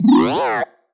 sci-fi_driod_robot_emote_27.wav